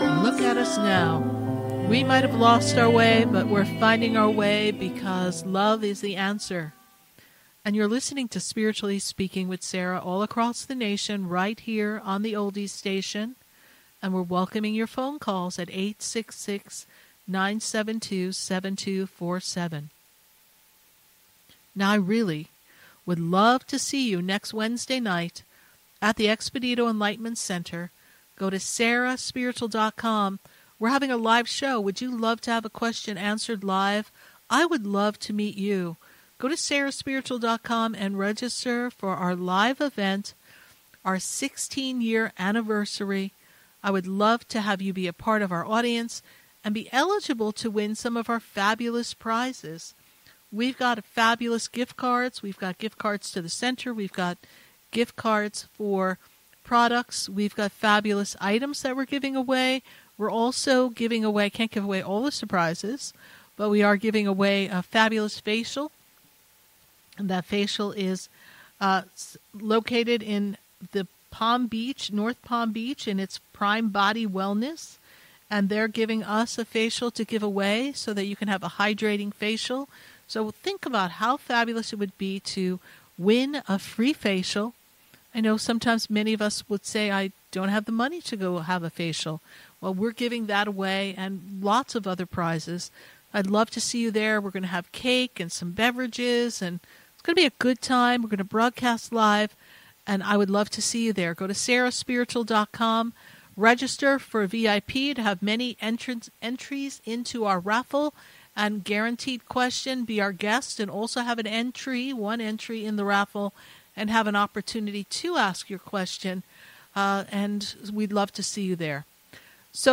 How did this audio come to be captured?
LIVE on the radio